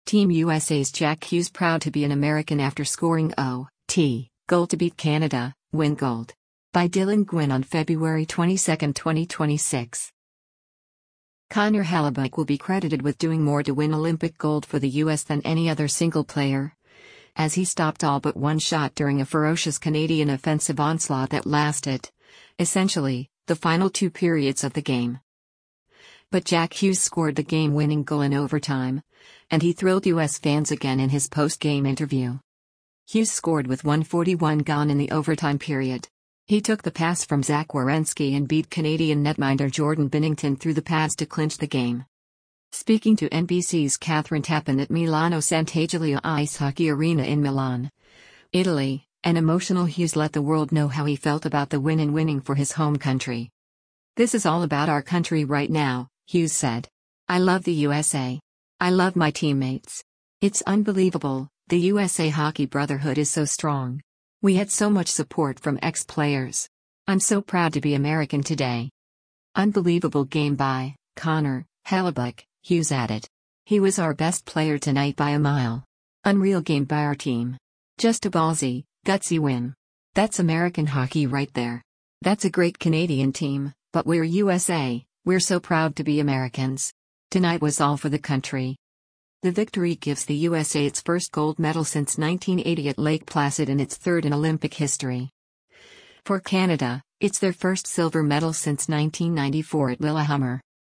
Speaking to NBC’s Kathryn Tappen at Milano Santagiulia Ice Hockey Arena in Milan, Italy, an emotional Hughes let the world know how he felt about the win and winning for his home country.